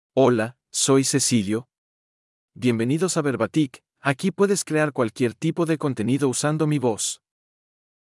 Cecilio — Male Spanish (Mexico) AI Voice | TTS, Voice Cloning & Video | Verbatik AI
Cecilio — Male Spanish AI voice
Cecilio is a male AI voice for Spanish (Mexico).
Voice sample
Listen to Cecilio's male Spanish voice.